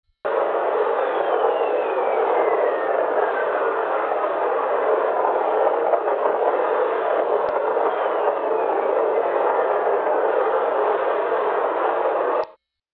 Это электромагнитные колебания, переведенные в слышимый диапазон.
Звуковые эффекты молний в верхних слоях атмосферы Юпитера